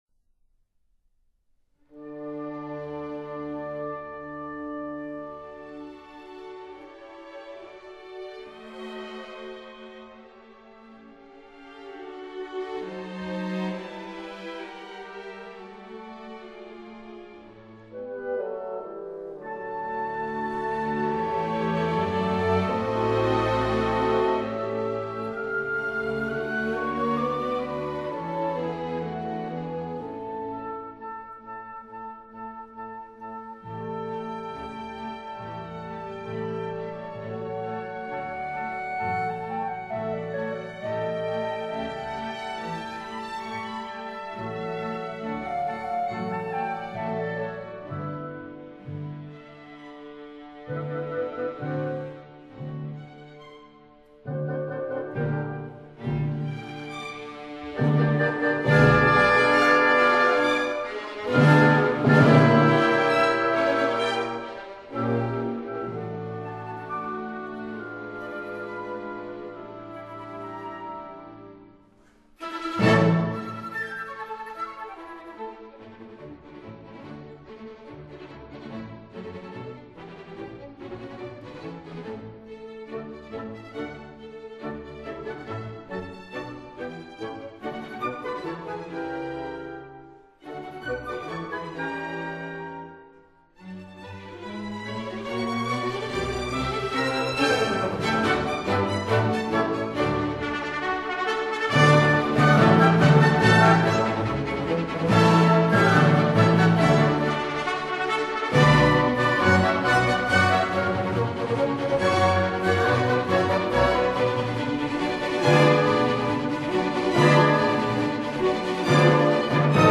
•(01) Symphony No. 1 in D major
•(05) Symphony No. 2 in E flat major